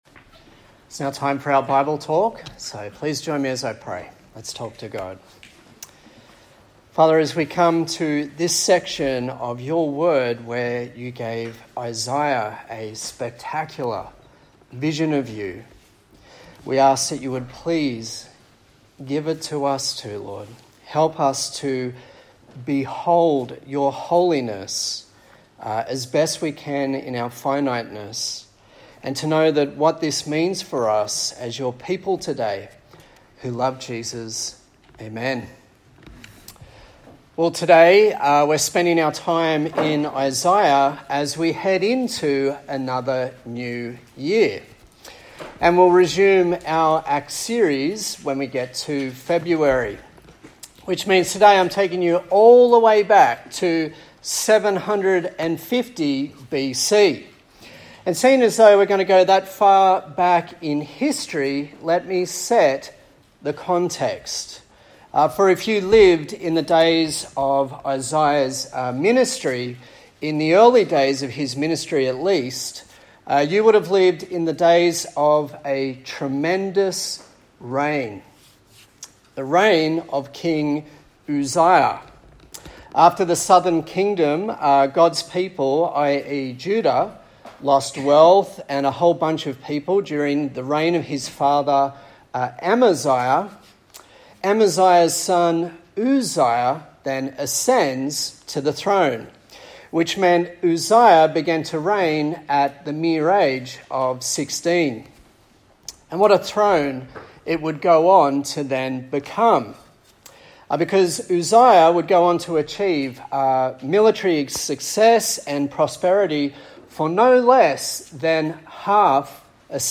A sermon on the book of Isaiah
Service Type: Sunday Morning